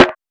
Snares
SNARE.120.NEPT.wav